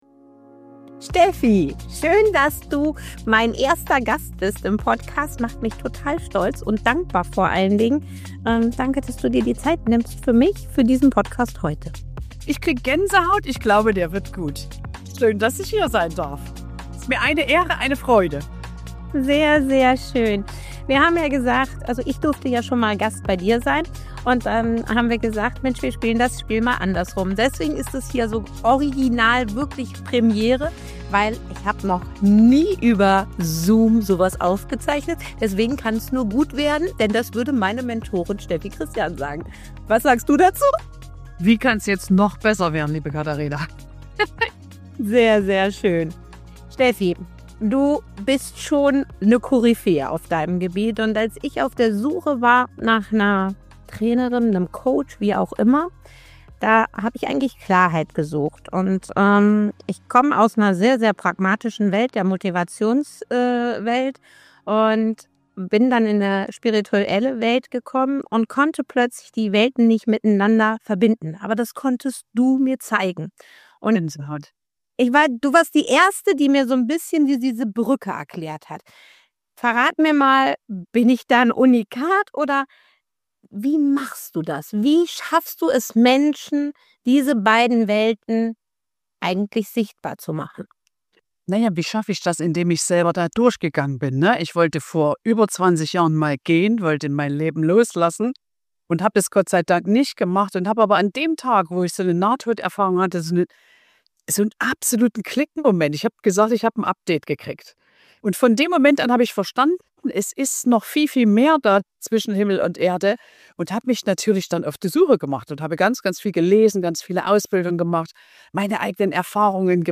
Ein Interview voller Bilder, Klarheit, Tiefe und echter Menschlichkeit.